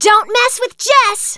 jess_kill_04.wav